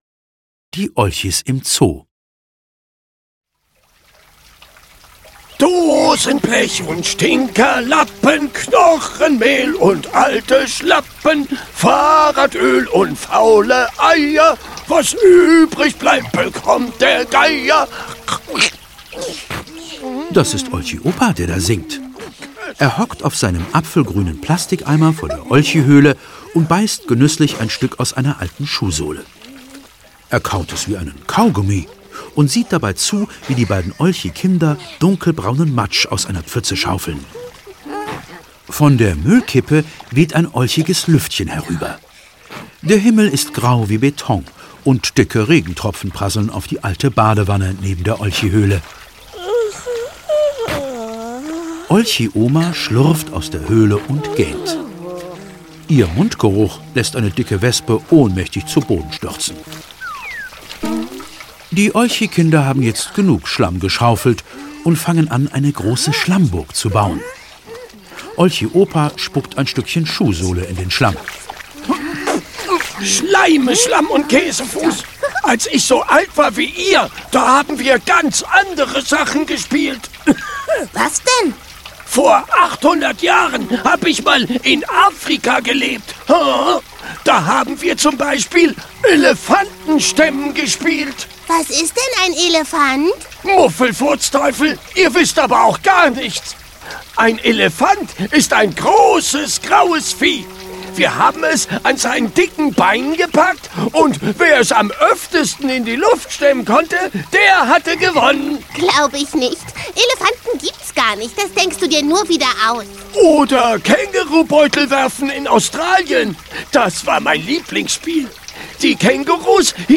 Die Olchis im Zoo - Erhard Dietl - Hörbuch